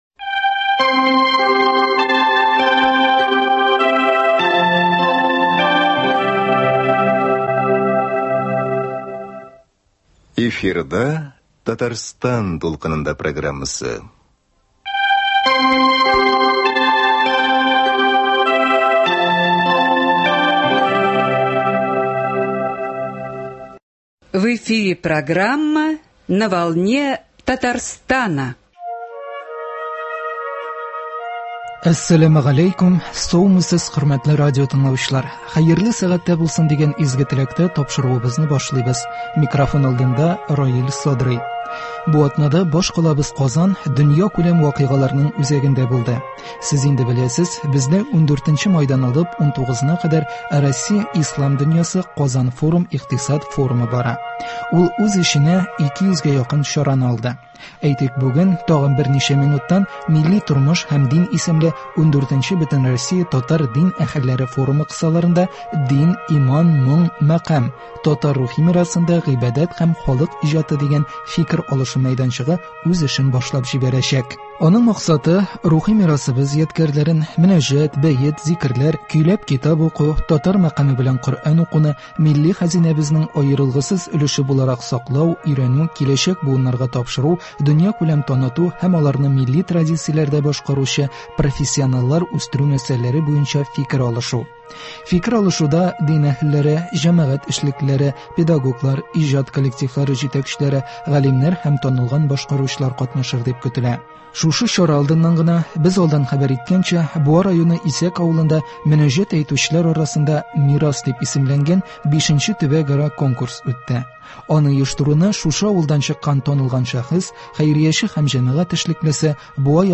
Рухи мирасыбыз сагында. Мөнәҗәт әйтүчеләр арасында Буа районы Исәк авылында үткән V Төбәкара «Мирас» конкурсыннан репортаж.